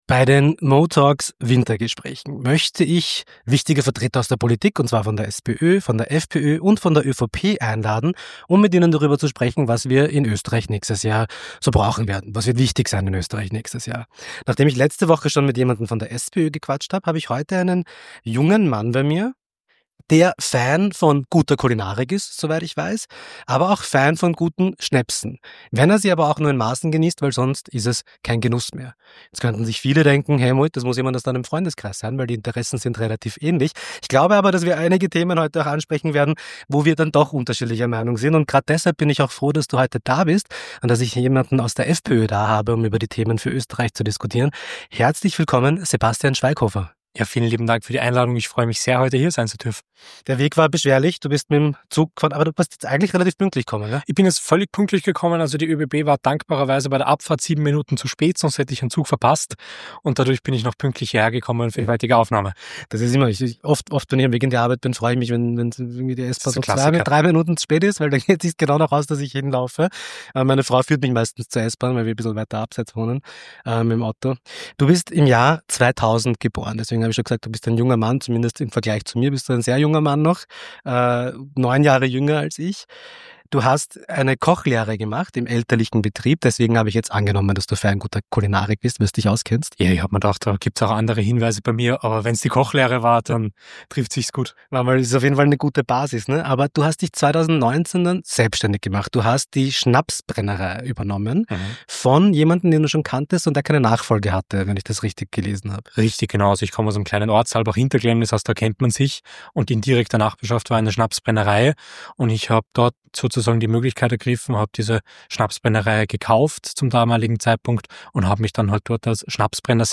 Beschreibung vor 4 Monaten In dieser Folge setze ich meine Wintergespräche mit Vertretern der österreichischen Politik fort – diesmal mit Sebastian Schwaighofer, Nationalratsabgeordneter der FPÖ. Wir sprechen offen über die politischen Spannungsfelder, in denen Österreich gerade steckt – auch, wenn unsere Meinungen aufeinanderprallen.
Teuerung: Wie können wir steigende Kosten in Wohnen, Energie & Alltag bremsen? Eine Diskussion mit deutlich unterschiedlichen Meinungen, die wir nunmal weiterführen müssen.